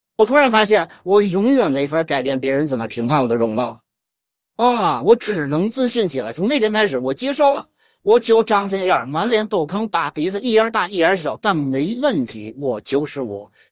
最后再来一个语音克隆
2.克隆后音频：马保国